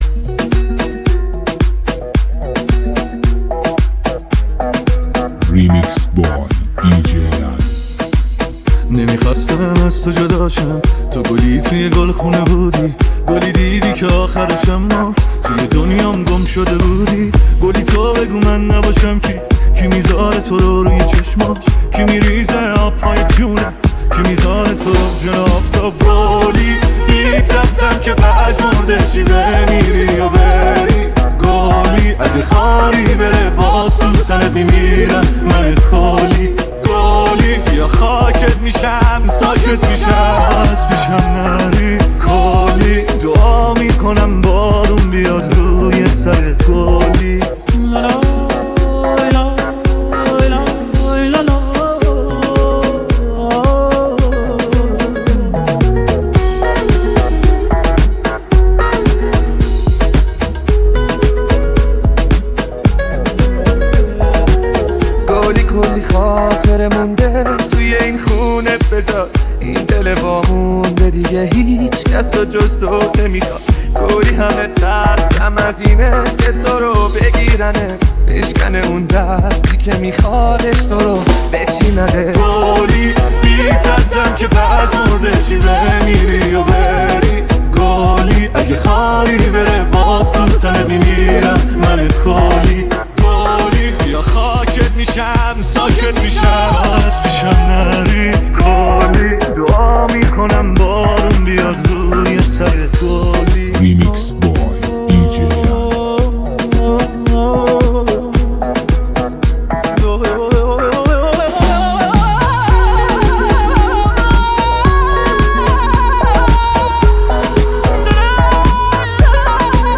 لذت بردن از موسیقی پرانرژی و بیس قوی، هم‌اکنون در سایت ما.